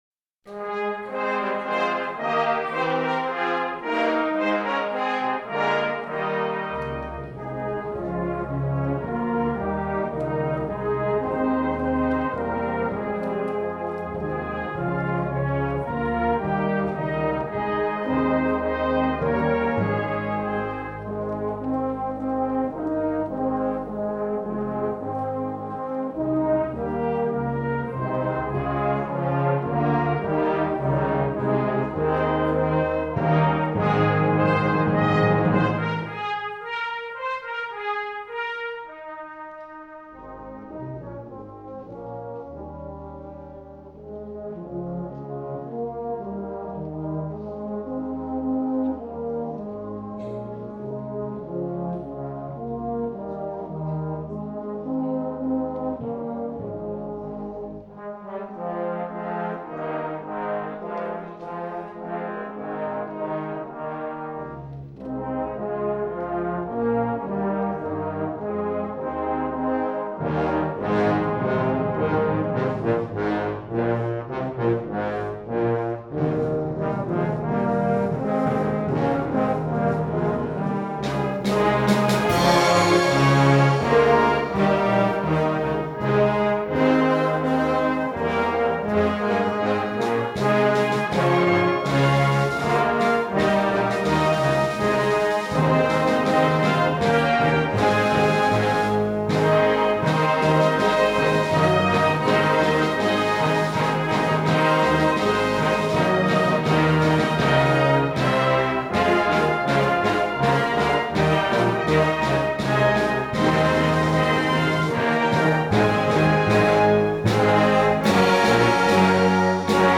(Fanfare Prelude)
19th Century Welsh hymn tune